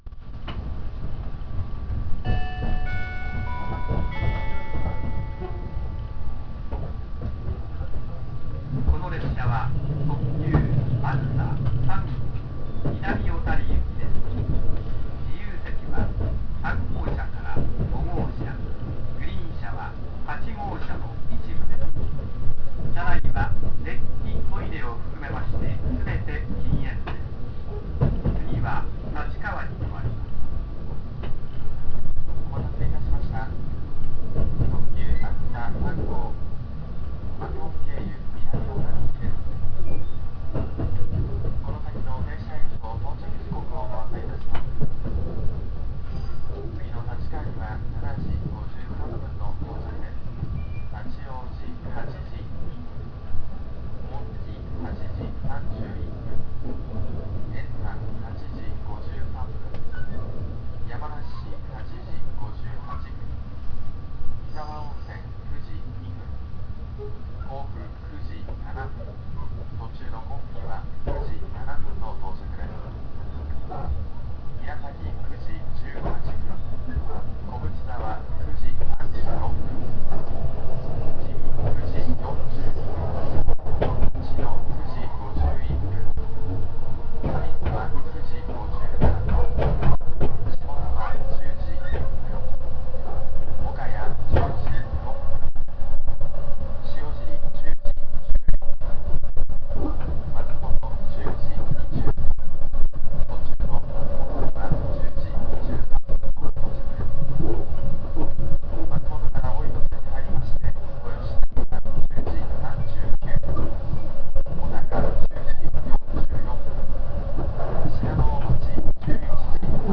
・E257系車内放送
255系のように専用のチャイムがあるわけでもなく、JR東日本の特急型車両としてはお馴染みのチャイムと放送です。